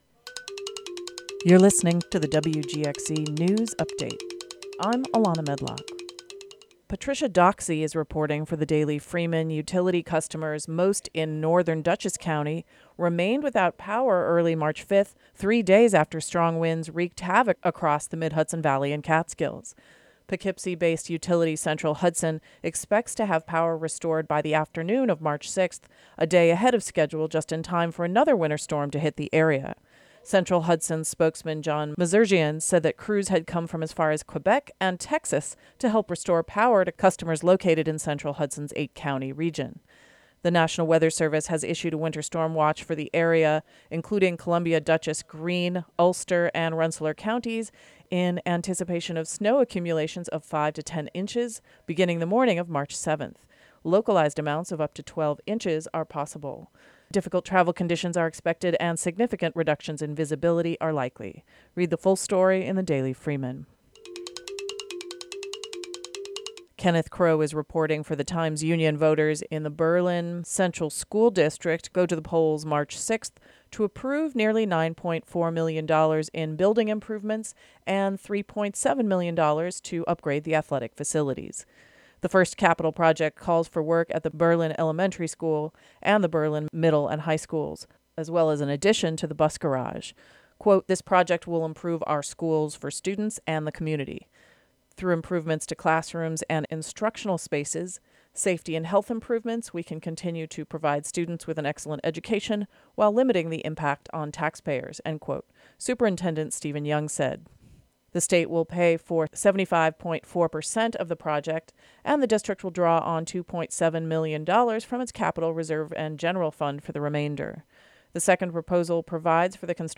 The local news update for the Hudson Valley.